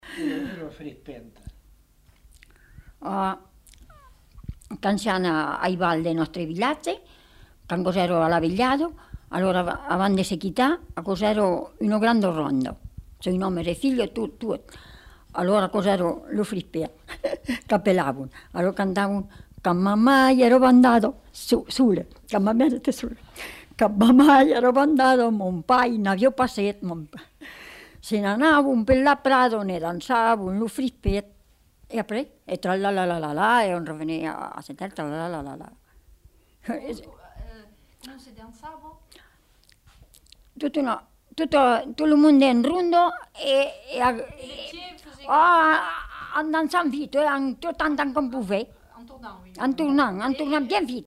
Aire culturelle : Haut-Agenais
Lieu : Gavaudun
Genre : chant
Effectif : 1
Type de voix : voix de femme
Production du son : chanté
Danse : pripet